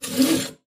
in_bowsaw_stroke_02_hpx
Bow saw squeaks while sawing hardwood. Tools, Hand Wood, Sawing Saw, Squeak